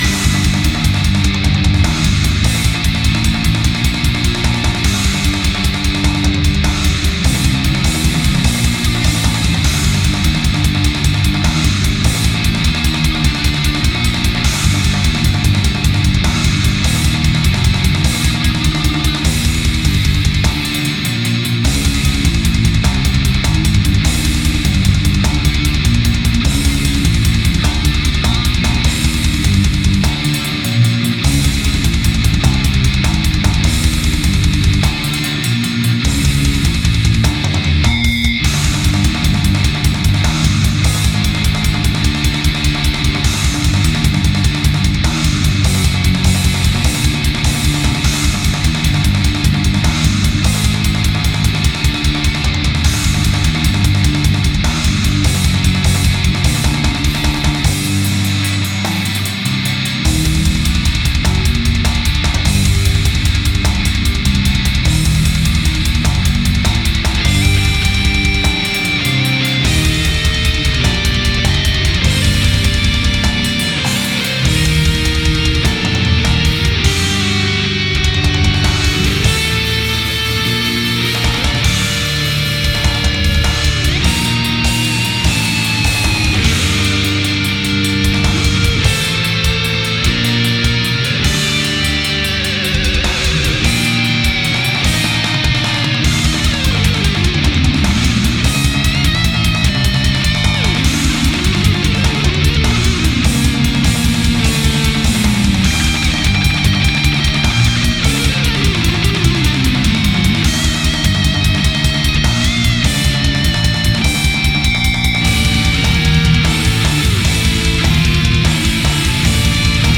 Гитары
Бас-гитара, программирование ударных